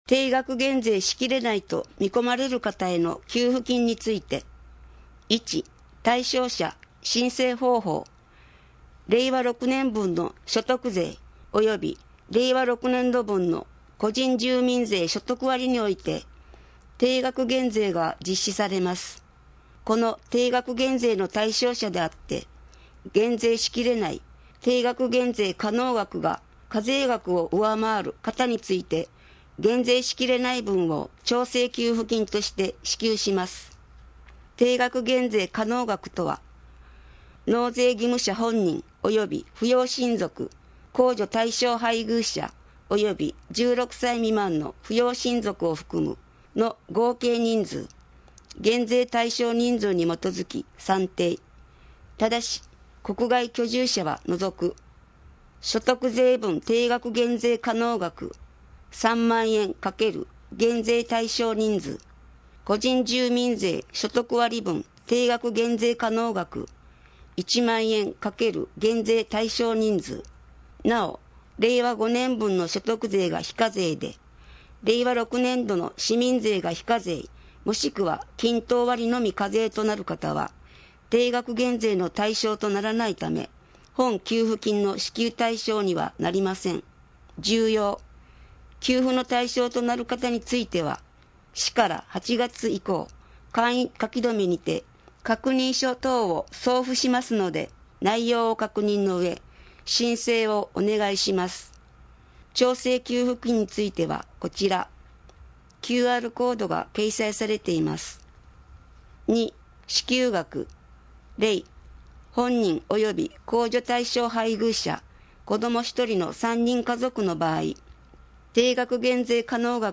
なお「声の広報」は、朗読ボランティアどんぐりの協力によって作成しています。